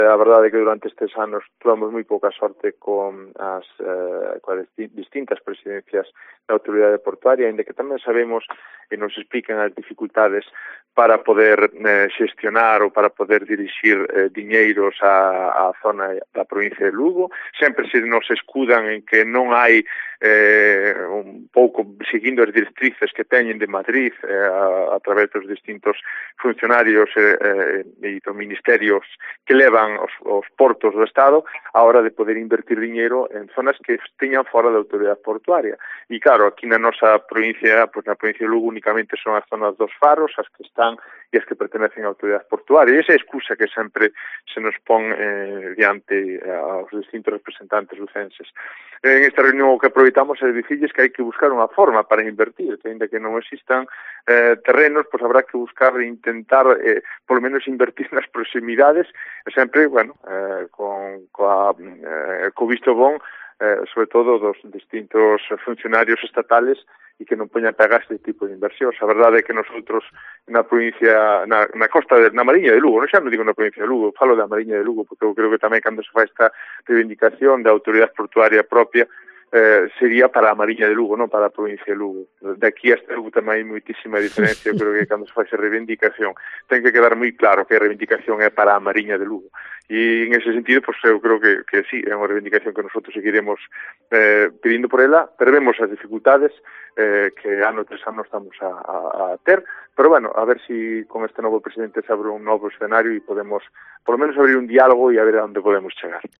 Declaraciones de ALFONSO VILLARES , alcalde de Cervo